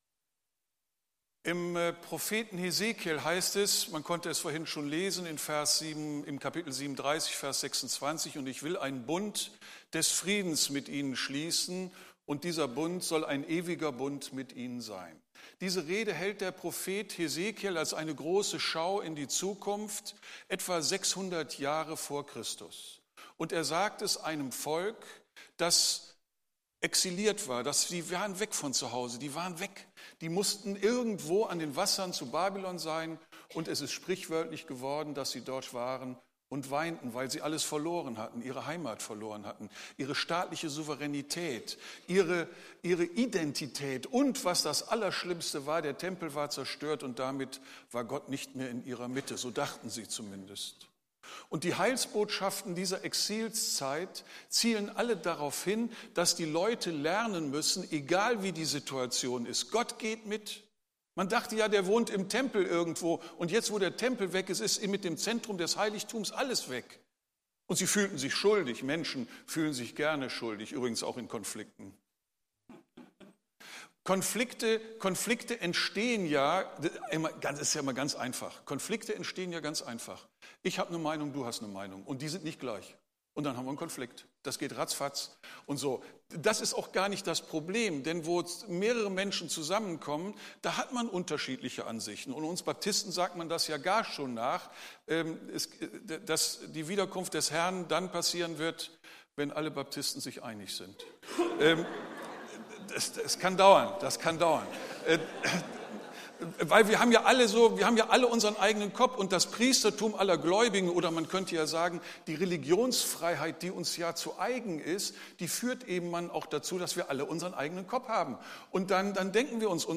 Predigttext: Hesekiel 37, 24-28